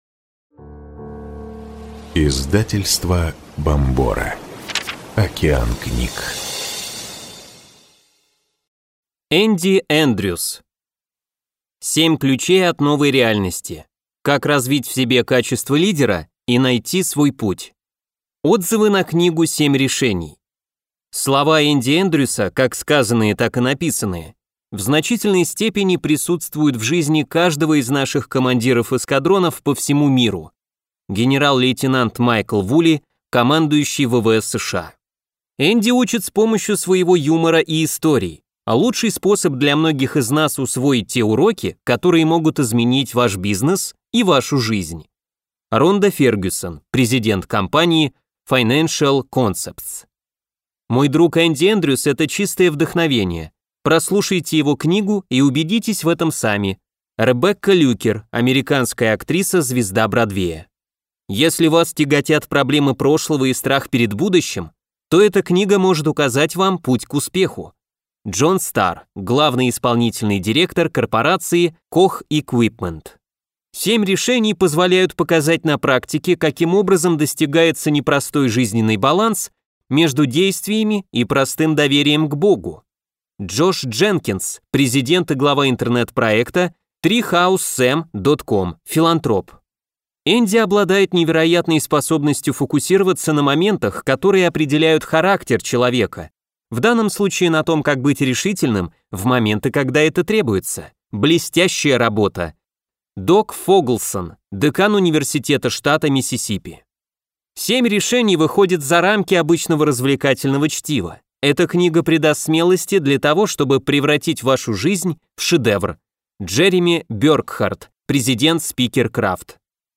Аудиокнига 7 ключей от новой реальности. Как развить в себе качества лидера и найти свой путь | Библиотека аудиокниг